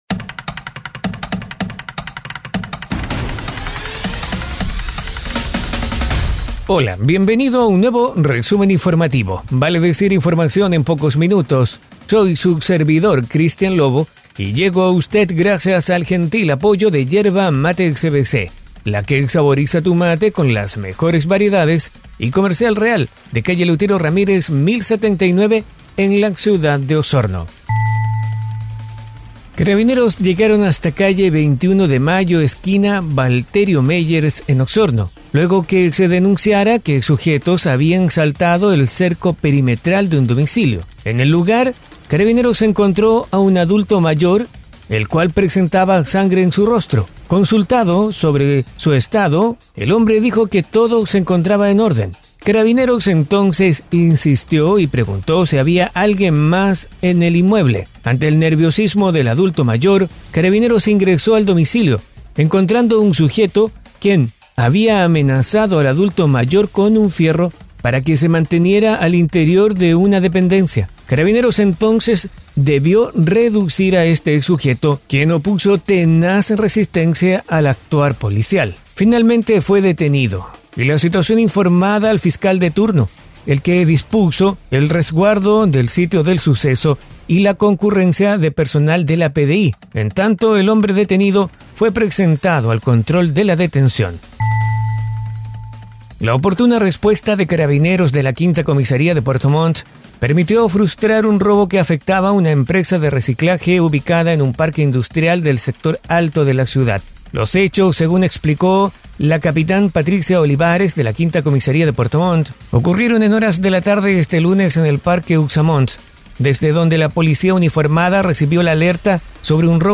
Resumen Informativo 🎙 Podcast 07 de marzo de 2023